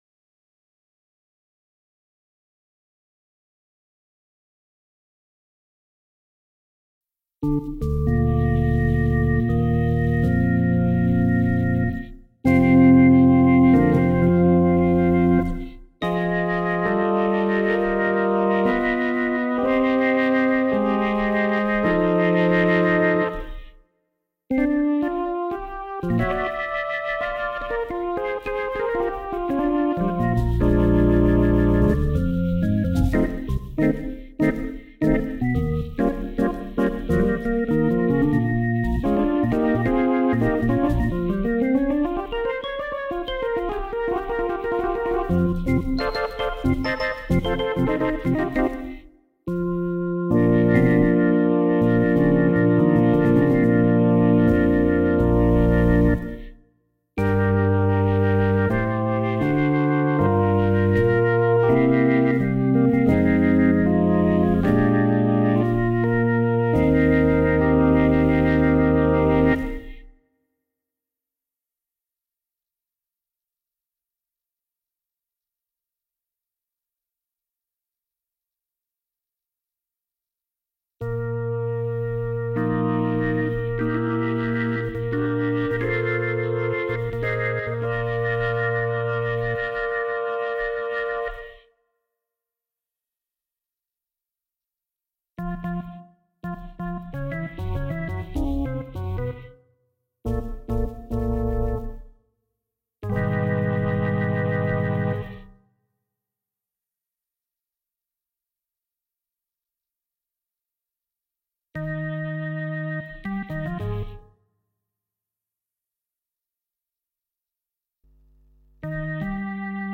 A linux organ compared with a (dwnl.) PC3 organ experiment.
So I though what if I use some rack elements like organ, saturation, exciter, and Leslie, push them through my studio simulation effect chains (mainly (but not only) adding FFT-averaged, dynamic low-mid tube and mid frequency components), with in this case the purpose of preventing harmful sine-wave tones (when amplified loud) and touch of sound-type the past.
For fun I took a halfway-to-a-blues-organ PC3 organ sound (this one: tgospeltv4nb2.pc3) and somewhere halfway this little tryout, I mixed that one in:
Found the experiment interesting; it sounds like the Leslie itself is moving in circles inside the room.